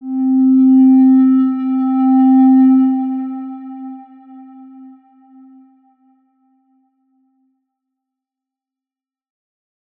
X_Windwistle-C3-pp.wav